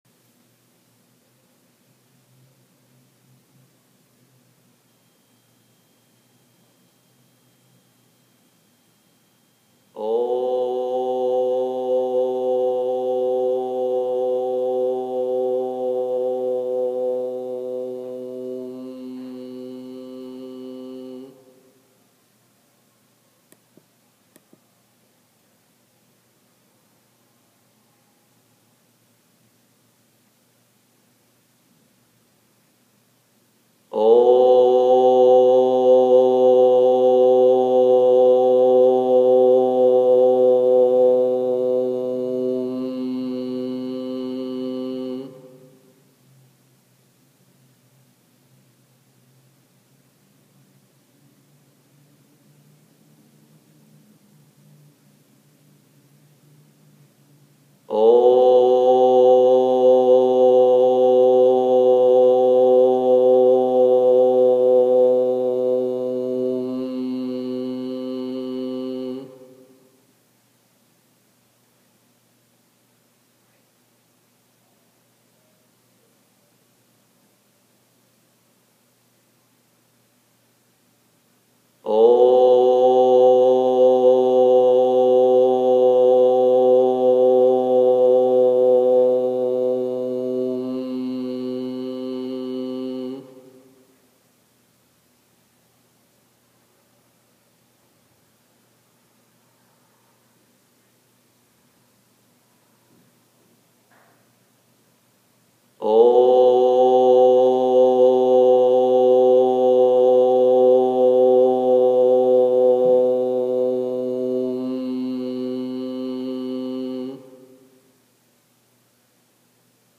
11 minutos de OM
11-min-OM.m4a